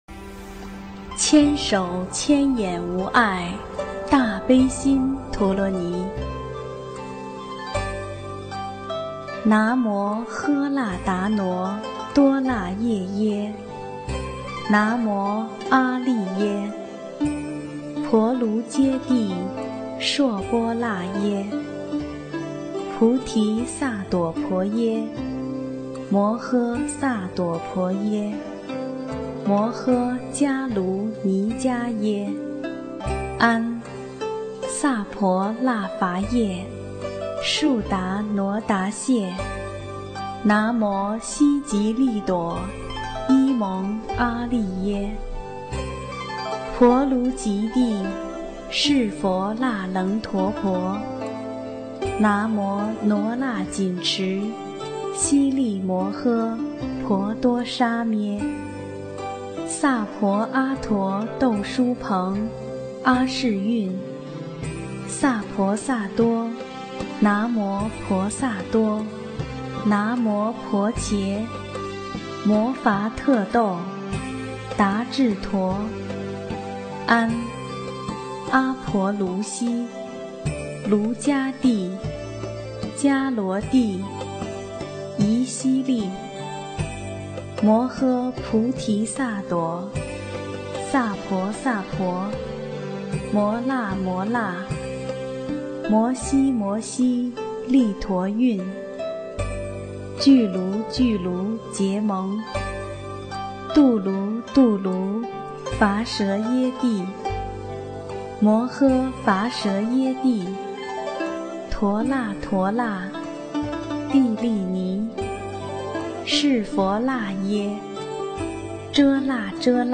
音频：经文教念-《大悲咒》